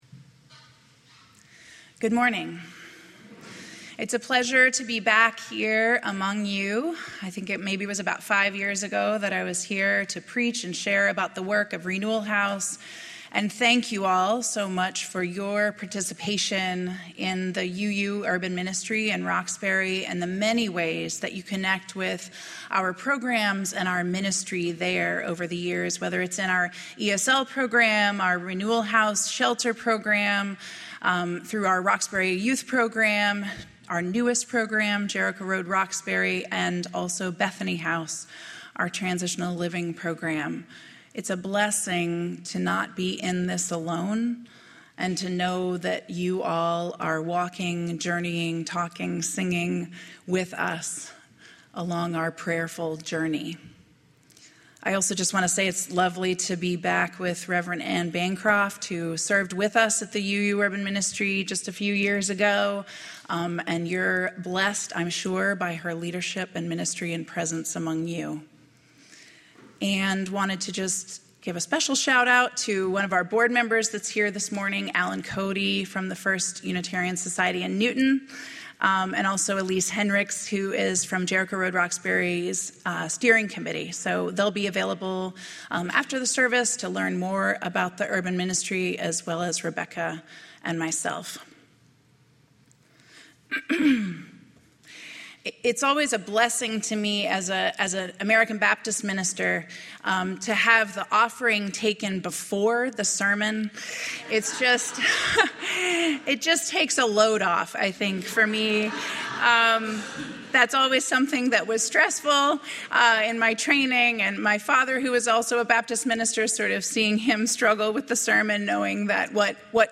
preaches on the tried-and-true process of restorative justice.